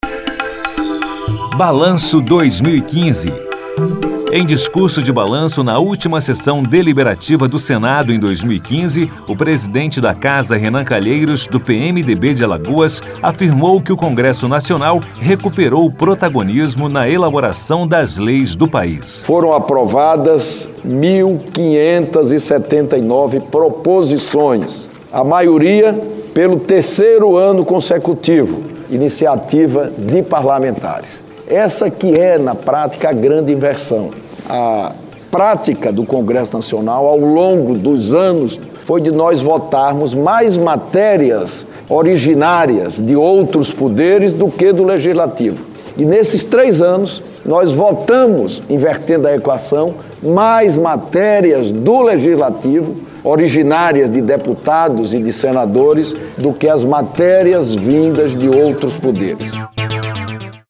Em seu último discurso de 2015 no Plenário do Senado, ele lembrou os avanços obtidos durante o ano.